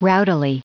Prononciation du mot rowdily en anglais (fichier audio)
Prononciation du mot : rowdily